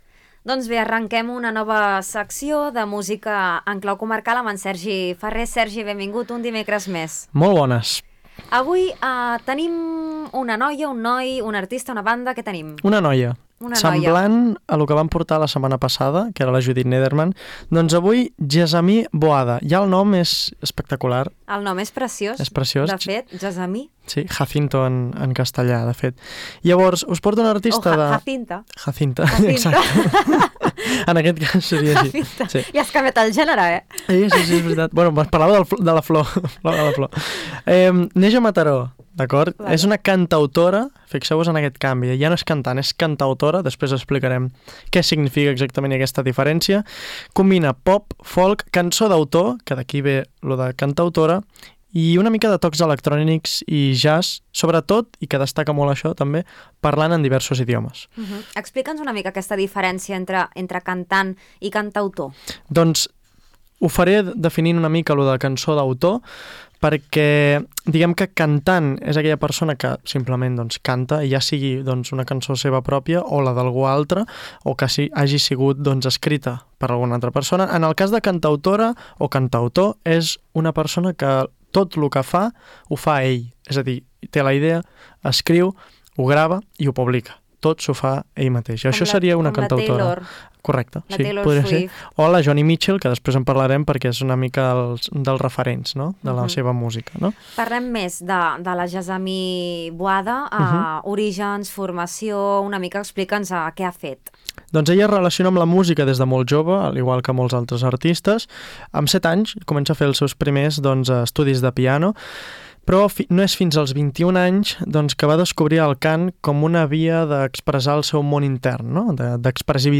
cantautora catalana
una veu delicada i poderosa alhora
Amb una veu càlida i expressiva